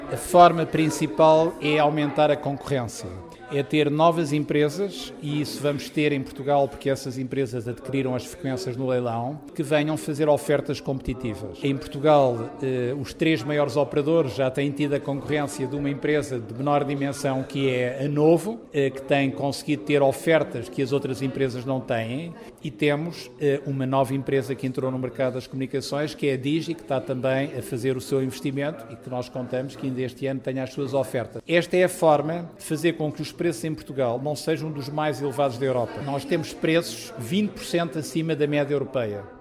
De acordo com o presidente da ANACOM, isso acontece porque há pouca concorrência: